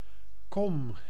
Ääntäminen
IPA: /kɔm/